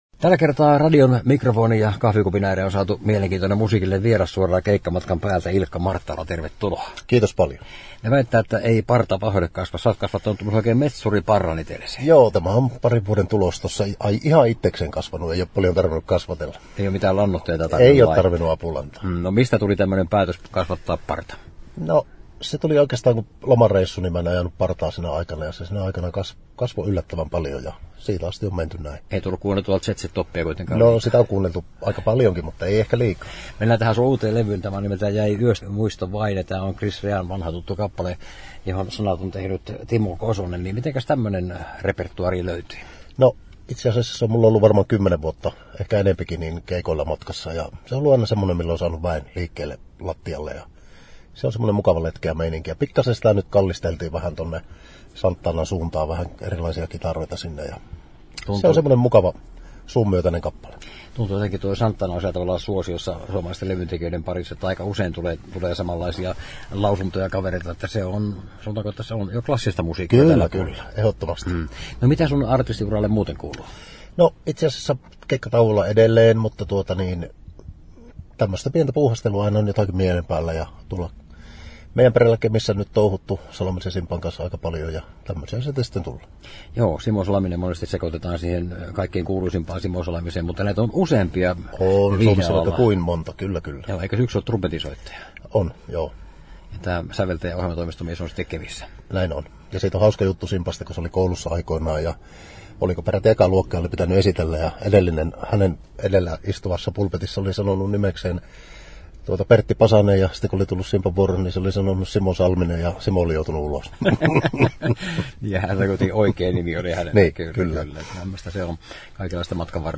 Haastattelu, Henkilökuvassa, Viihdeuutiset, Yleinen